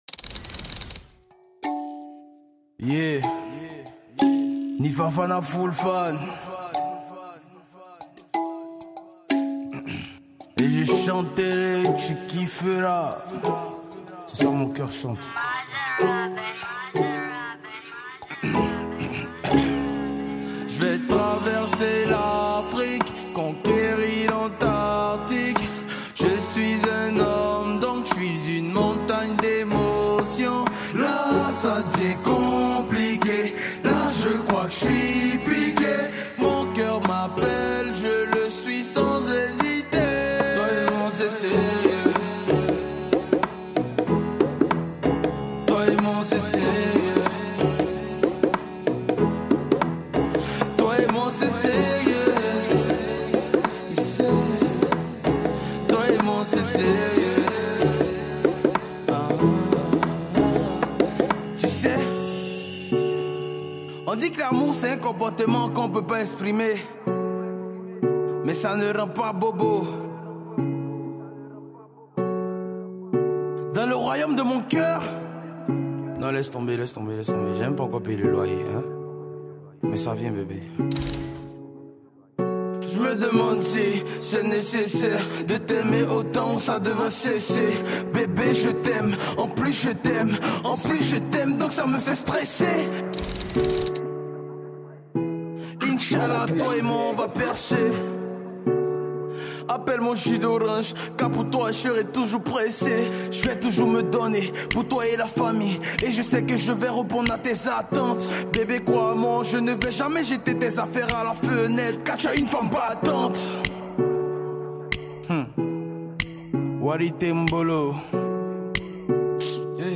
Le rappeur Ivoirien vous offre ce son romantique.